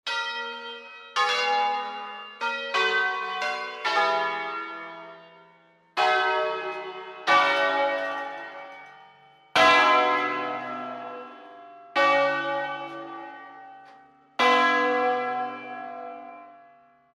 Entrando nello specifico di Bergamo, il suono ‘a scala’ si è sempre mosso sul principio del suono ‘al botto’, vale a dire producendo accordi invece di suoni alternati ed equidistanti:
Scala
Chi tiene la III parte quando sente il primo botto della I. Chi tiene la IV parte quando sente il primo botto della II.
Chi tiene la VII parte quando sente il primo botto della V. Chi tiene l’VIII parte quando sente il primo botto della VI.